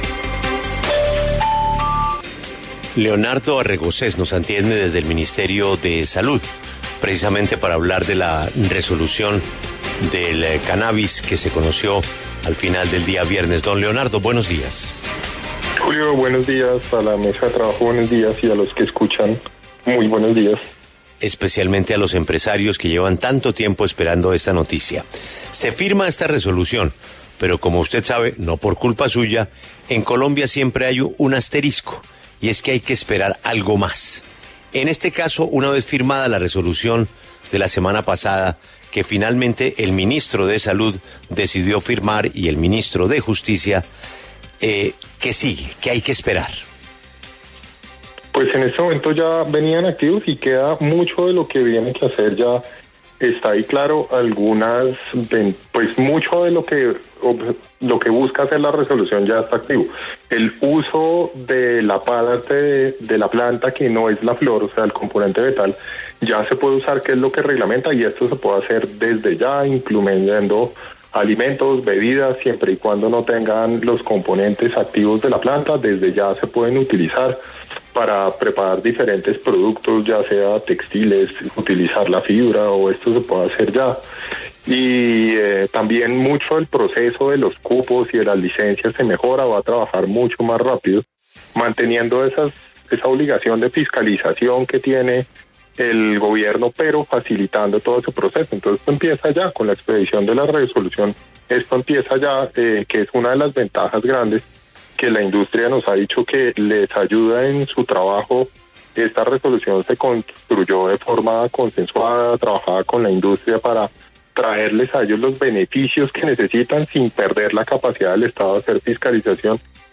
En La W, Leonardo Arregocés, director de Medicamentos del Ministerio de Salud, aseguró que, tras esta importante decisión, los trámites y licencias se expedirán con mayor prontitud.